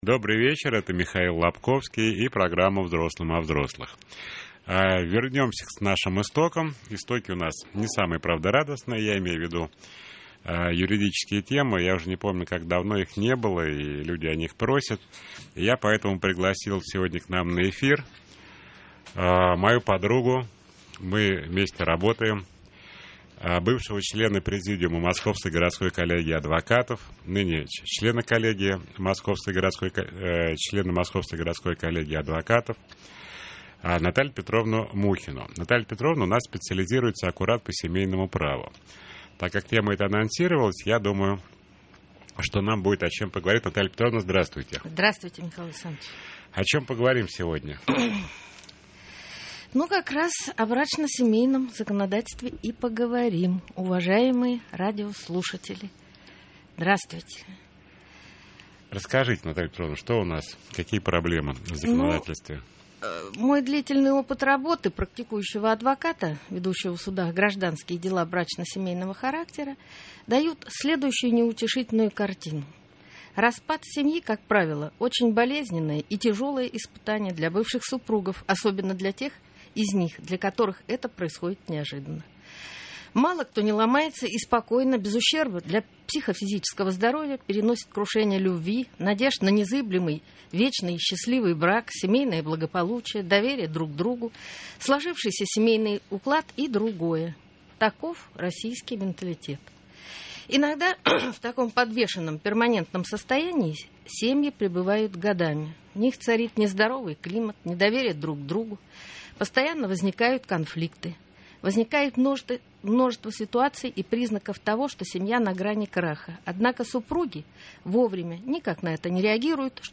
Конференция со слушателями